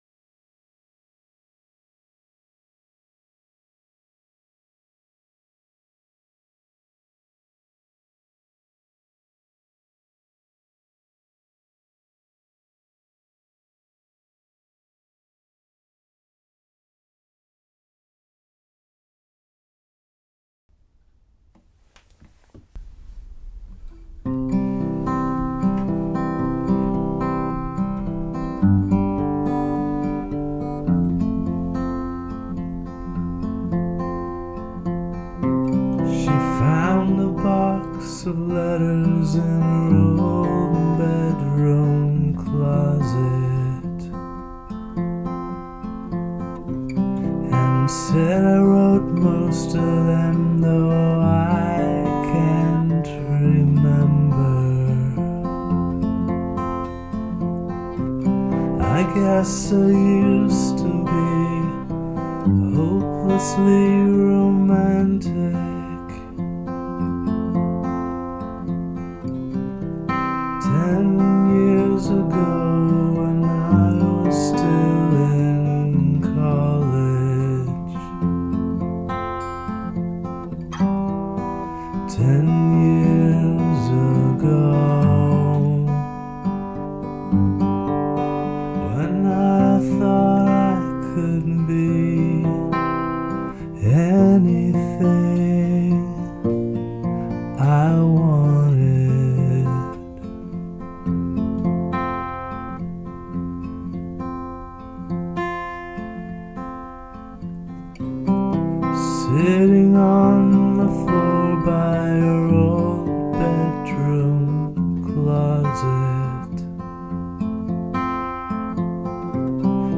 it’s just a sketch.
i think may have mumbled my way through most the song.